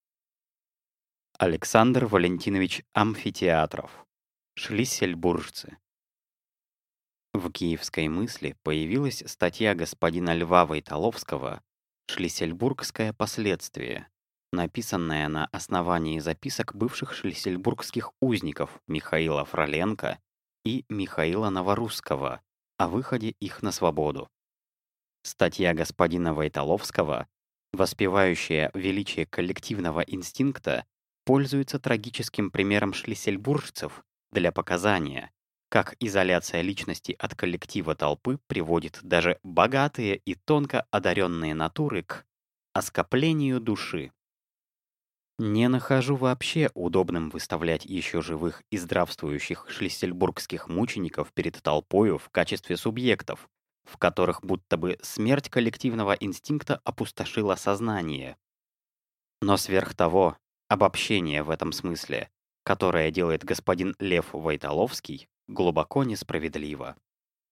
Аудиокнига Шлиссельбуржцы | Библиотека аудиокниг